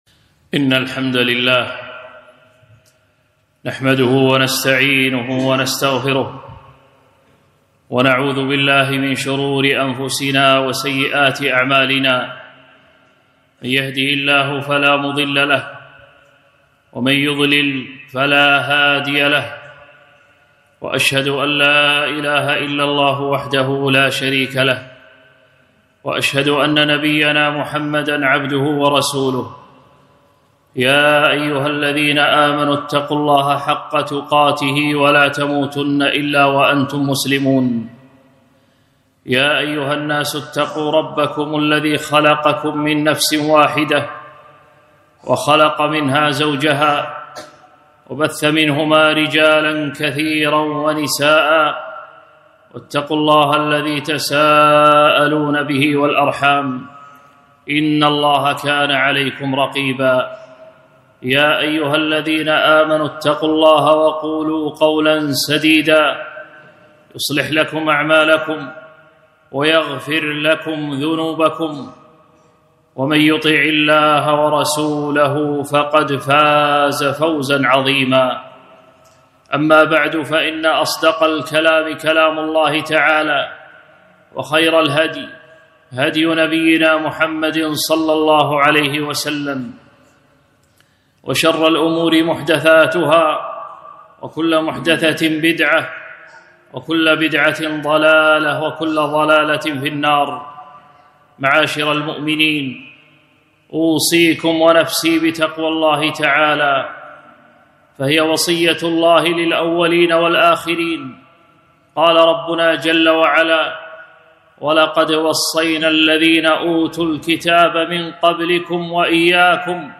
خطبة - الصدقة في رمضان